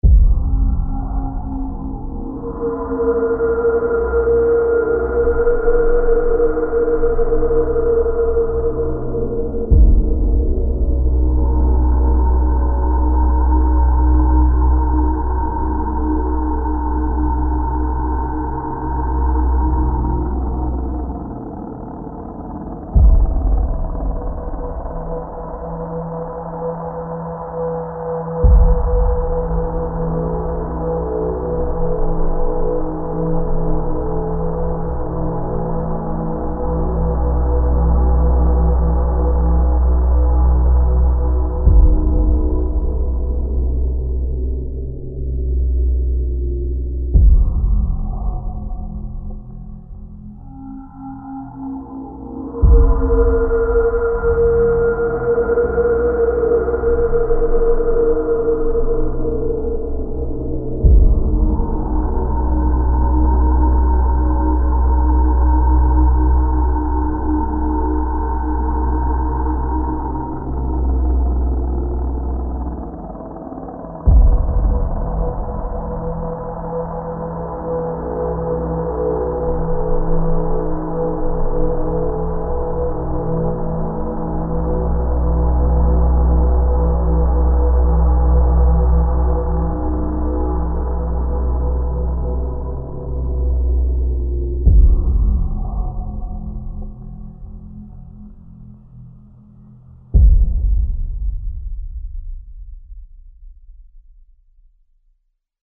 Haunting Ambo